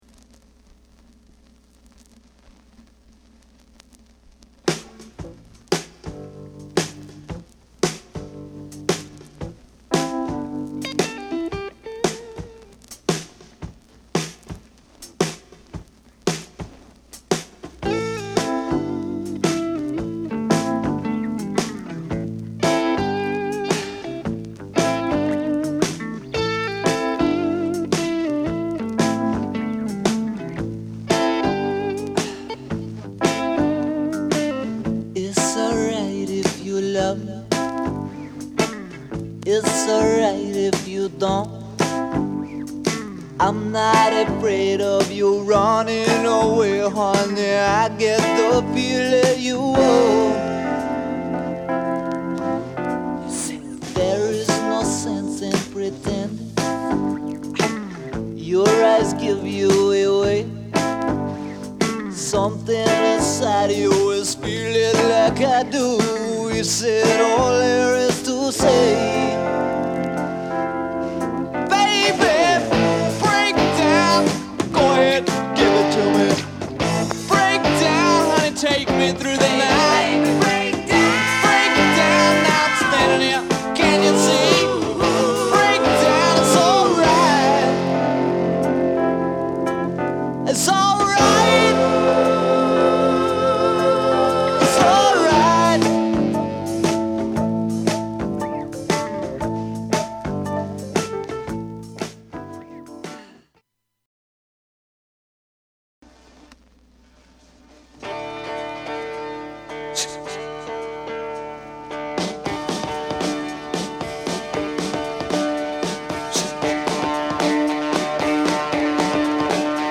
ROCK
シンプルにR&Rする本作は時代を超えて愛される名盤。